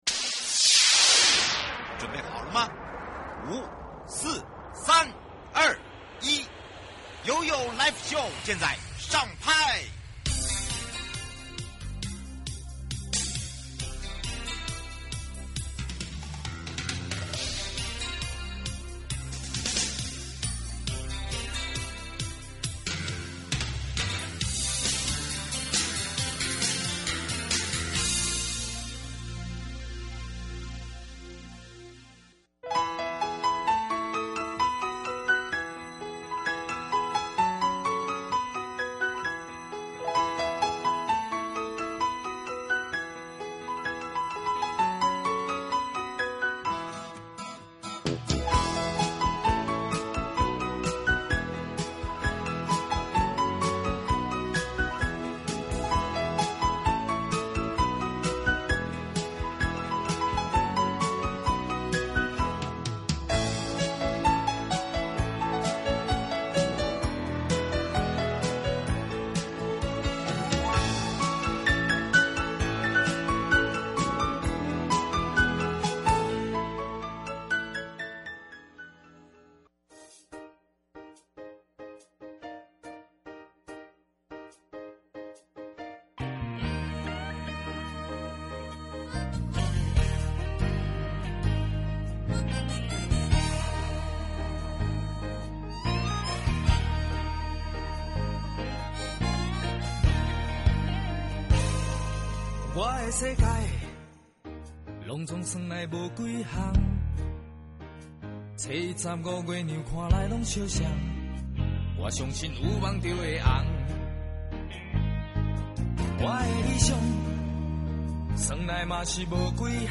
受訪者： 茂林管理處 柯建興處長